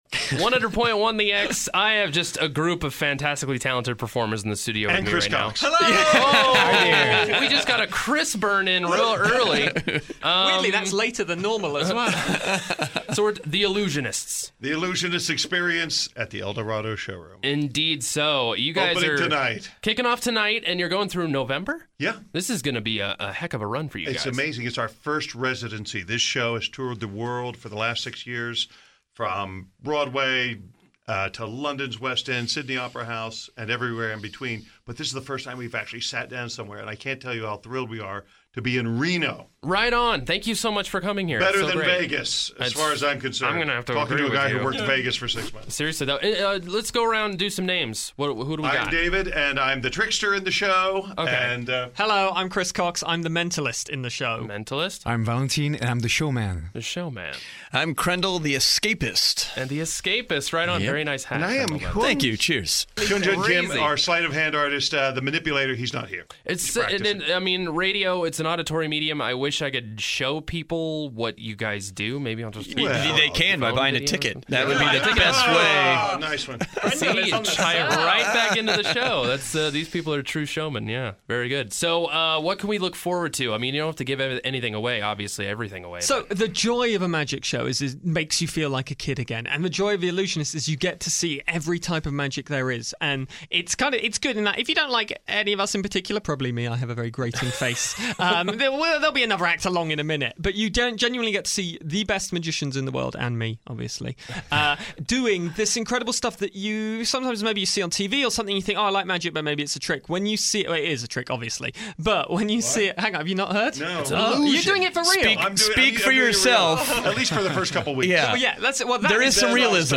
Interview: The Illusionists Experience